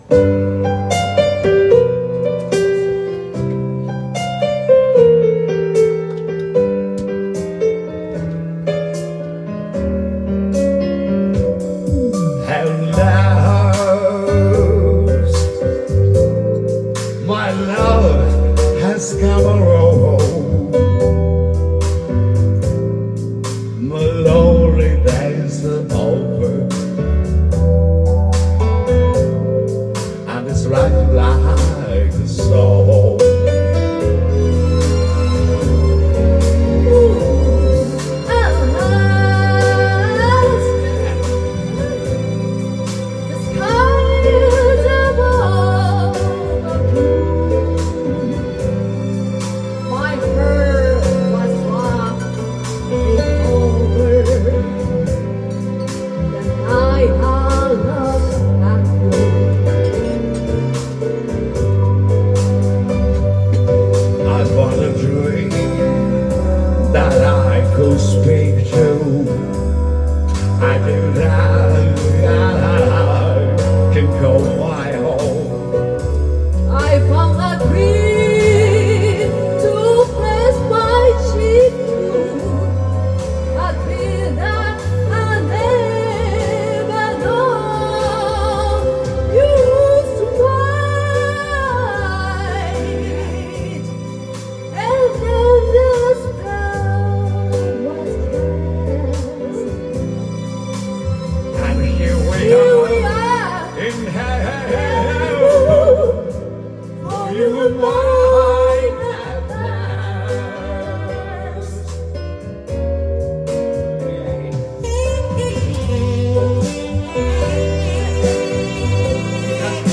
Duet & Chorus Night Vol. 19 TURN TABLE